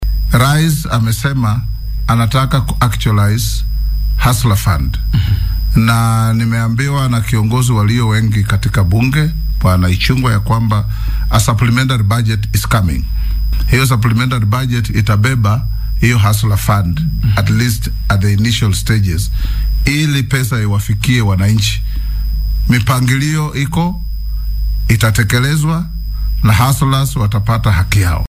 Guddoomiyaha baarlamaanka Moses Wetangula oo arrintan wax laga waydiiyay ayaa ku jawaabay.